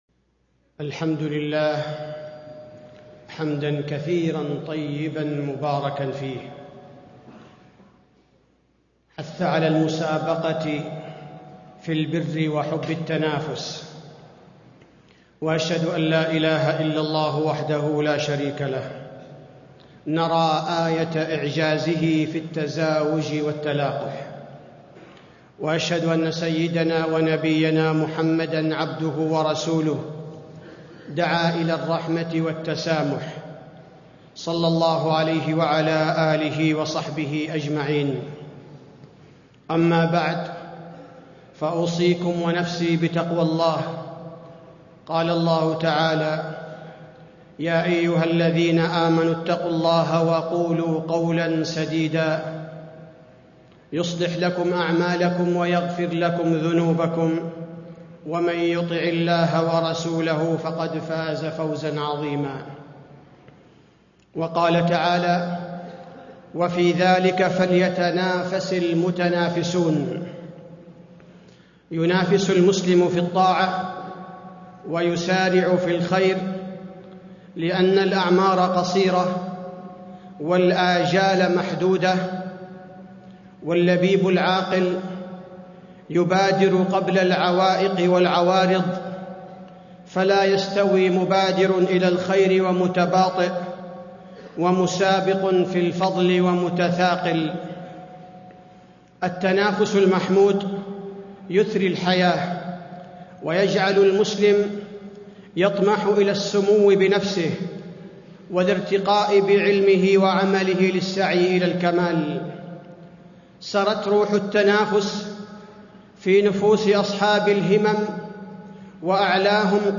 تاريخ النشر ١ شعبان ١٤٣٥ هـ المكان: المسجد النبوي الشيخ: فضيلة الشيخ عبدالباري الثبيتي فضيلة الشيخ عبدالباري الثبيتي التنافس المحمود والمذموم